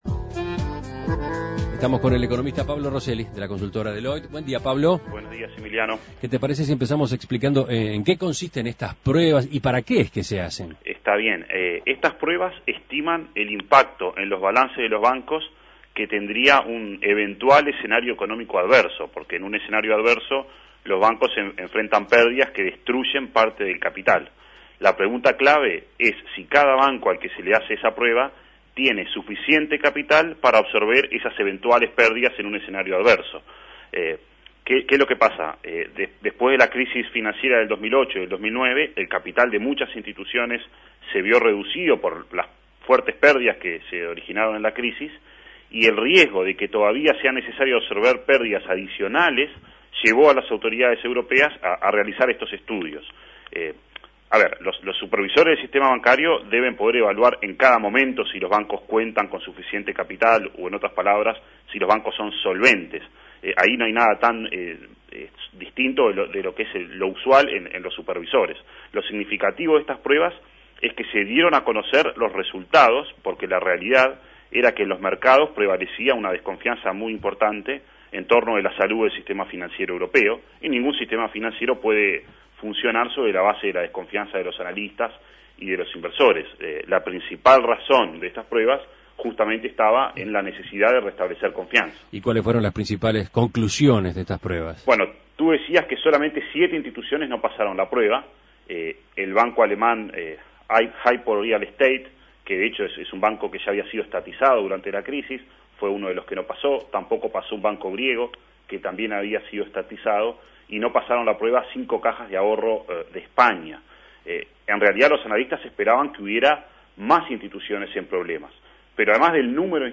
Análisis Económico Las pruebas de stress para los bancos europeos: ¿qué resultados arrojaron?